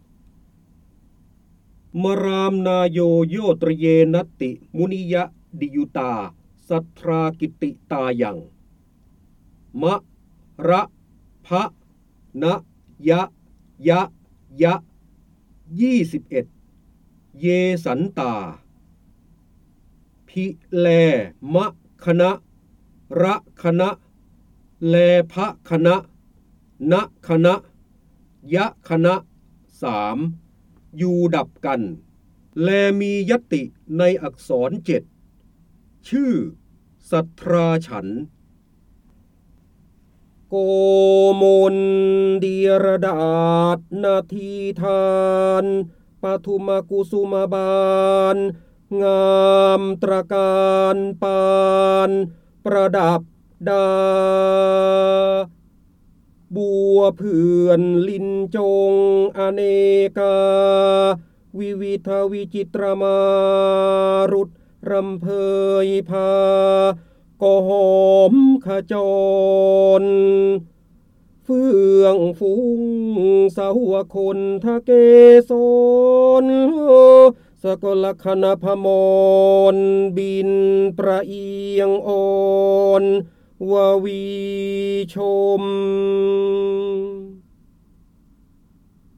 เสียงบรรยายจากหนังสือ จินดามณี (พระโหราธิบดี) มราม์นาโยโยต์รเยนัต์ติมุนิยดิยุตาสัท์ธรากิต์ติตายํ
คำสำคัญ : จินดามณี, พระเจ้าบรมโกศ, พระโหราธิบดี, ร้อยแก้ว, ร้อยกรอง, การอ่านออกเสียง
ลักษณะของสื่อ :   คลิปเสียง, คลิปการเรียนรู้